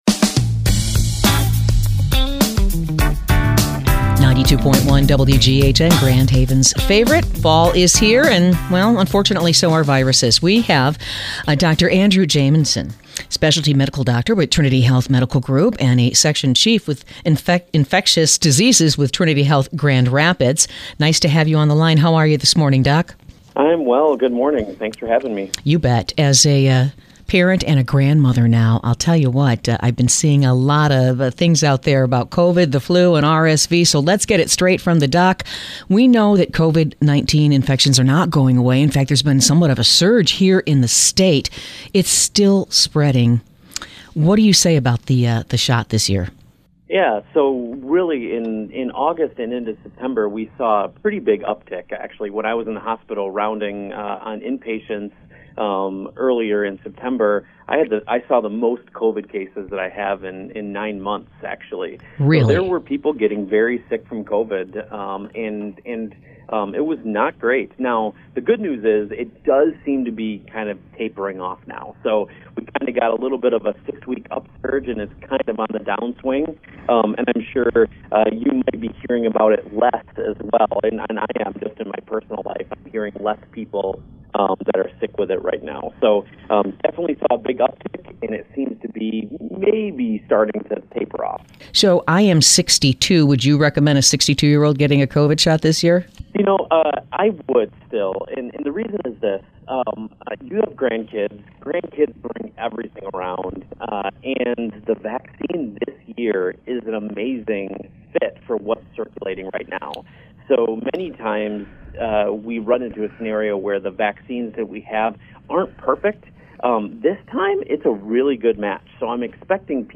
informative interview